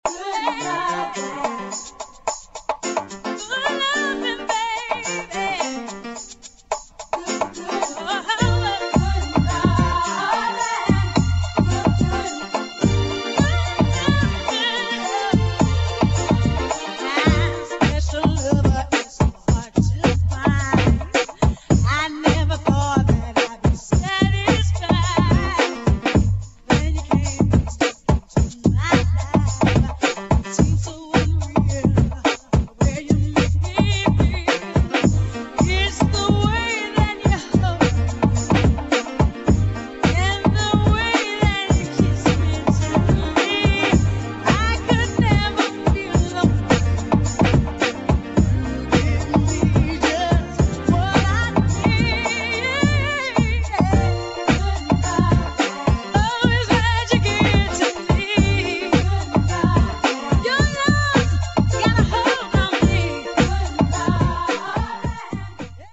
1. HOUSE | DISCO